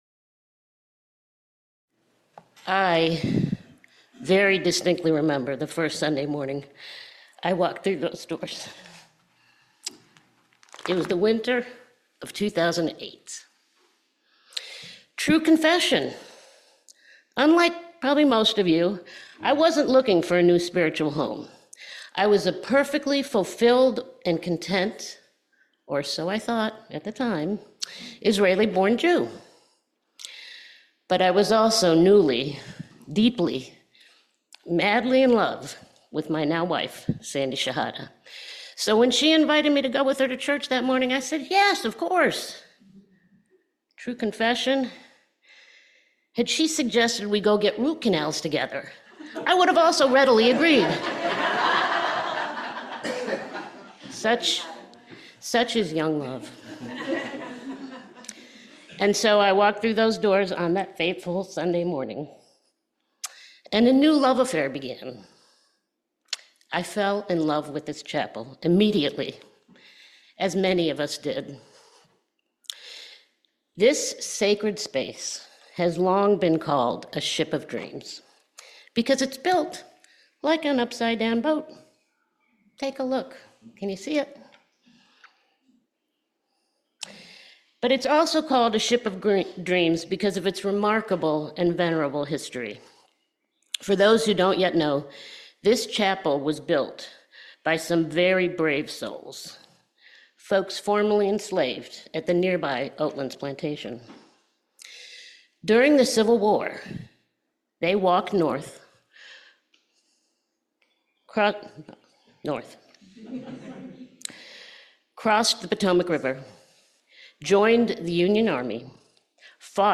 This sermon details the evolution of the Unitarian Universalists of Loudoun congregation and their long-standing efforts to balance the preservation of their historic 1890 chapel with the needs of …